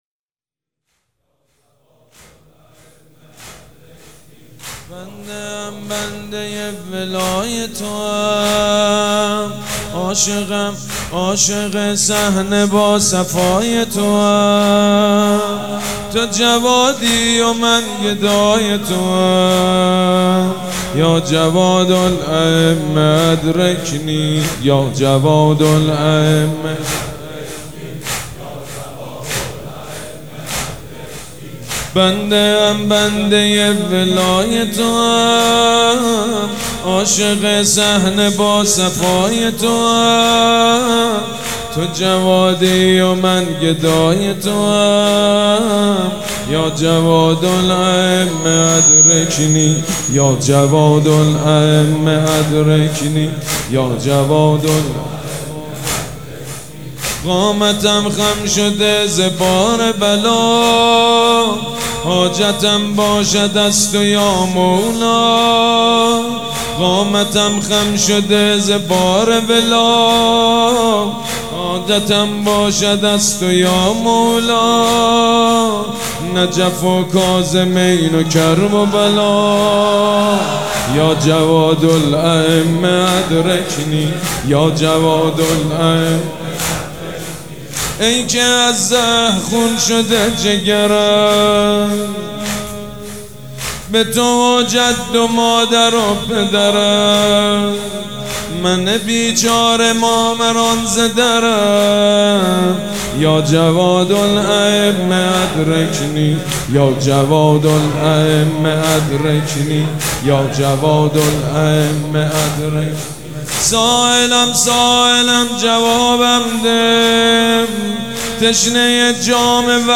مداح
شهادت امام جواد (ع)